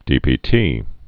(dēpē-tē)